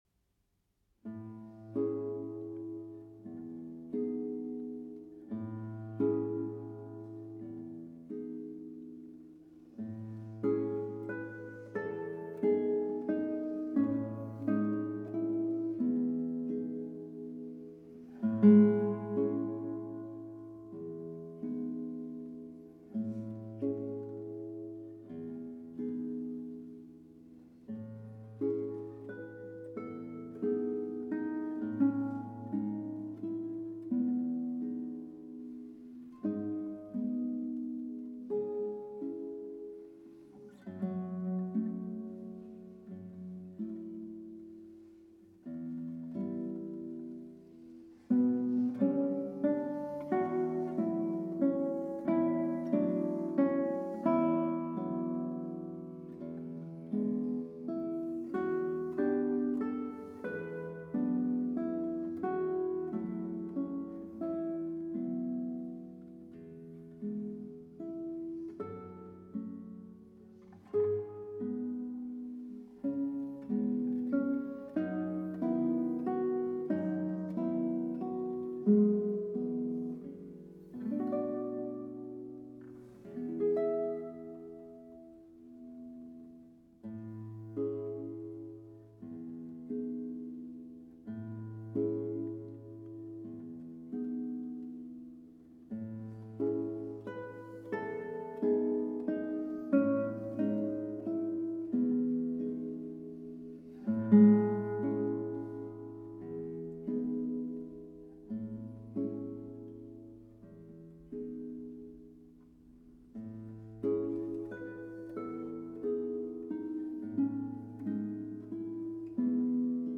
guitare.
Enregistré à Crichton Church, Écosse en 2020.